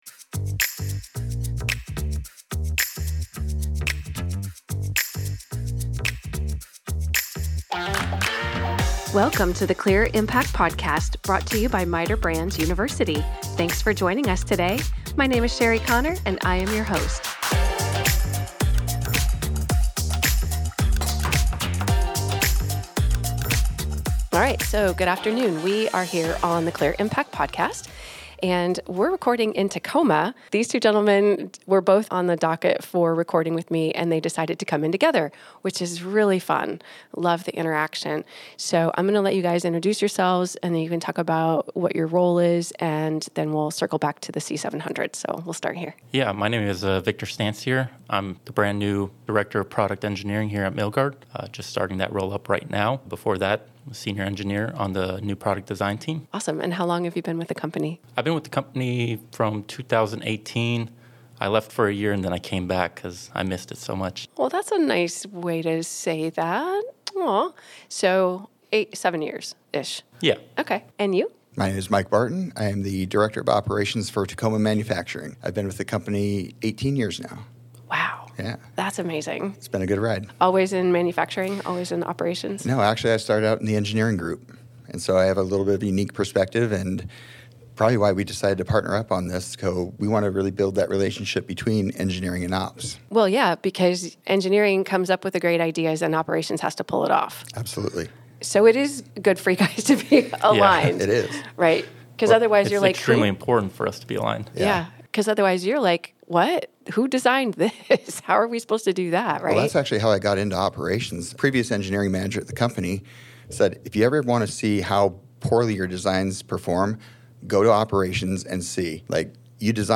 In this episode, you will hear how MITER Brands brought the Milgard C700 fiberglass window line to life. The conversation explores practical innovations, the role of automation, and how teamwork creates better products for both dealers and homeowners.